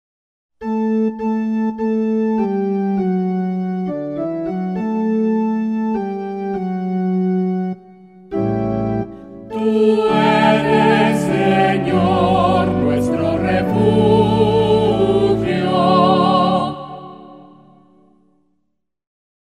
SALMO RESPONSORIAL Del salmo 89 R. Tú eres, Señor, nuestro refugio.